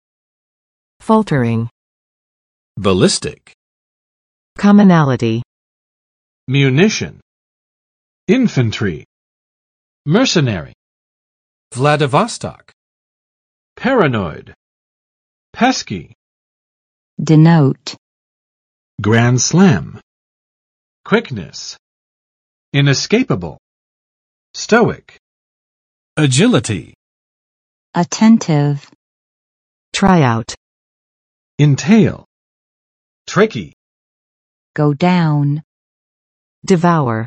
Vocabulary Test - September 6, 2023
[ˋfɔltɚɪŋ] adj. 踉跄的; 不稳定的
faltering.mp3